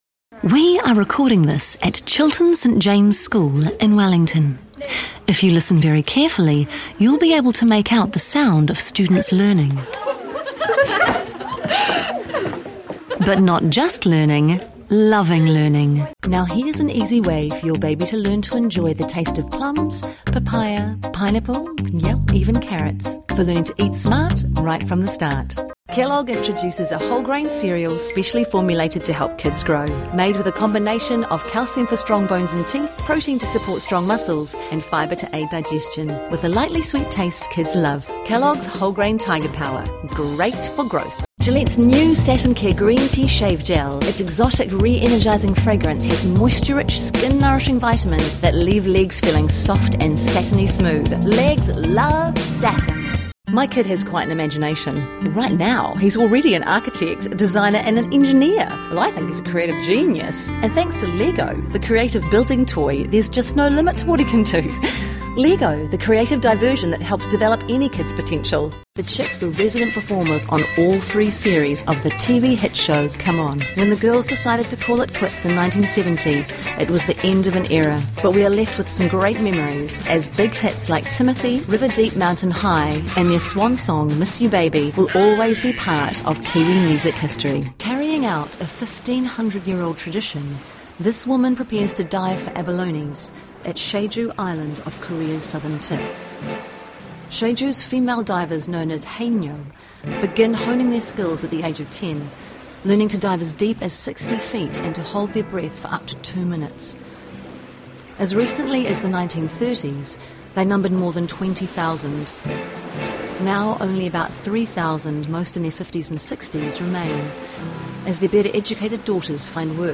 Demo
Adult
new zealand | natural
COMMERCIAL 💸